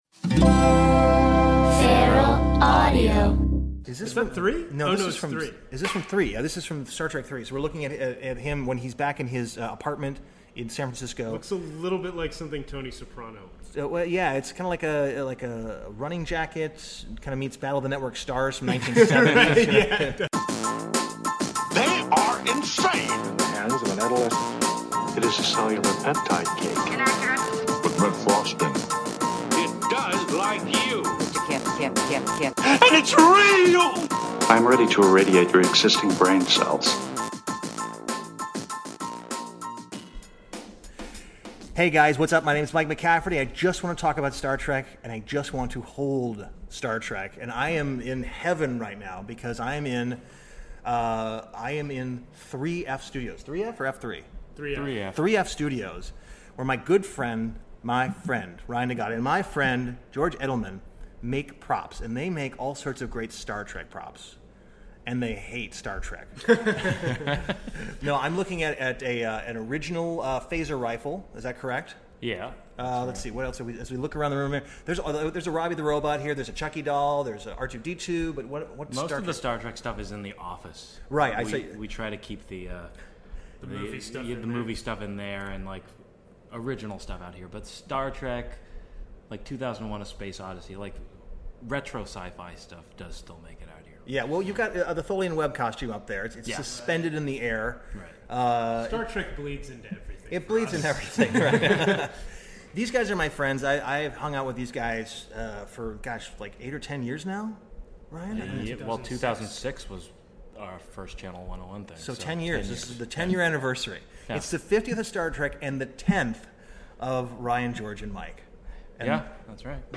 WARNING: Audio is a little echoy, but the studio was big.